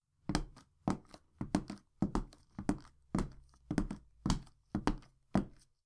Footsteps
Womens_shoes_1.wav